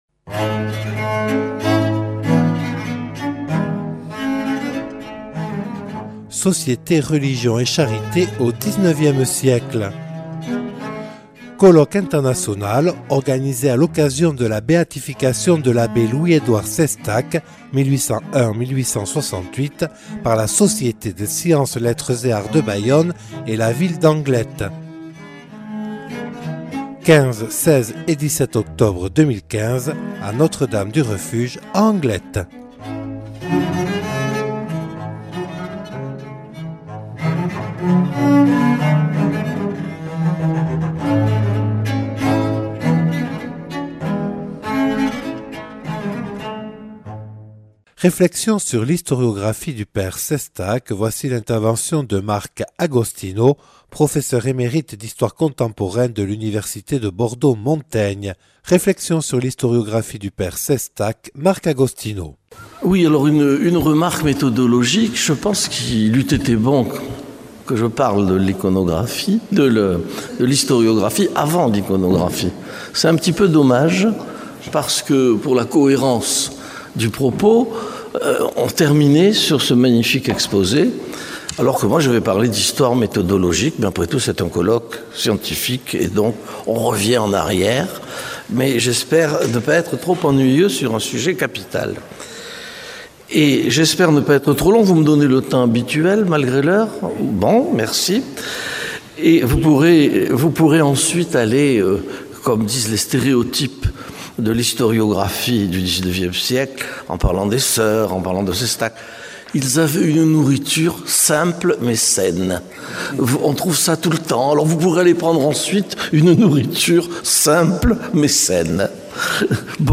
(Enregistré le 15/10/2015 à Notre Dame du Refuge à Anglet).